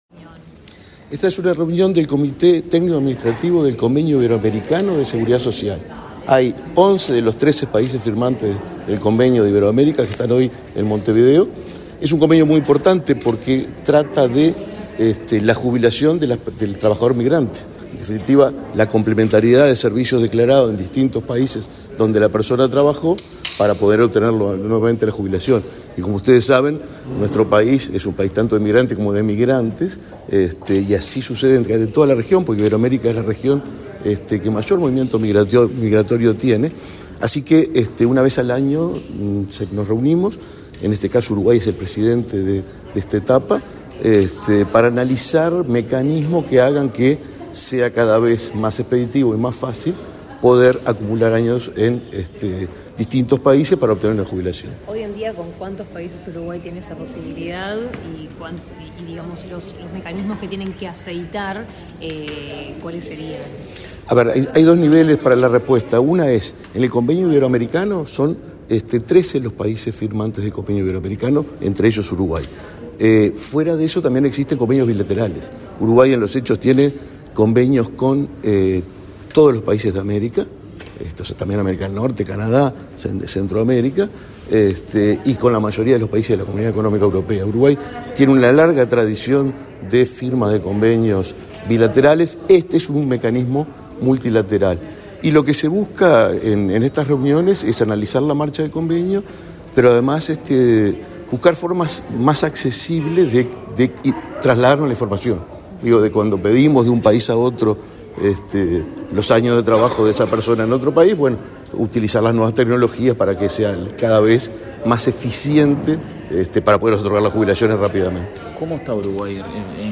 Declaraciones del presidente del BPS, Alfredo Cabrera
El presidente del Banco de Previsión Social (BPS), Alfredo Cabrera, dialogó con la prensa antes de participar de la apertura de la XIV reunión del